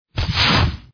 eqp_rpg2_fire.mp3